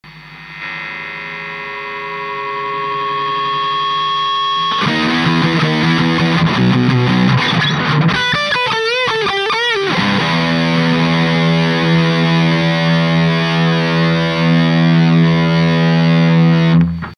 Guitar MoonStoratoShape
Amplifier VOX AD30VT UK'80S
つまみは両方ともフルです。
しかし、今回多少なりとも「音の暴れ」を認識できたかもしれません。